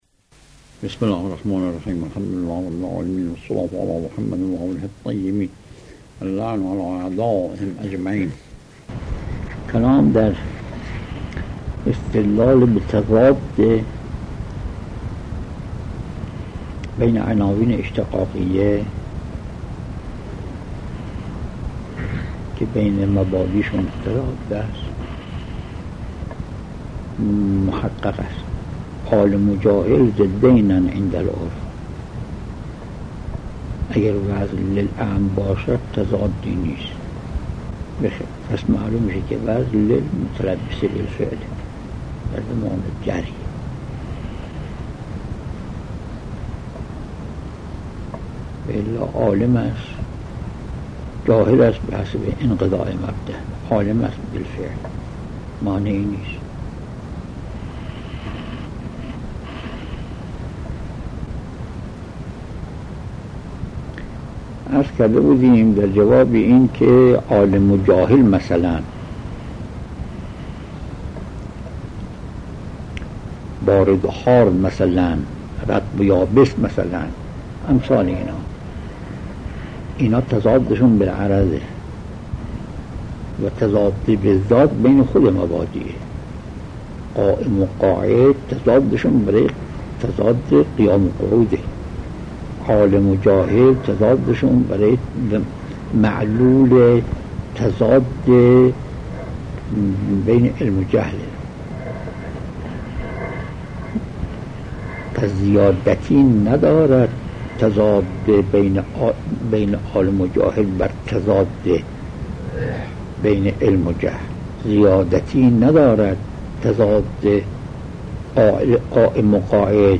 آيت الله بهجت - خارج اصول | مرجع دانلود دروس صوتی حوزه علمیه دفتر تبلیغات اسلامی قم- بیان